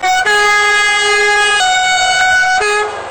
Train Horn Sound Effect Free Download
Train Horn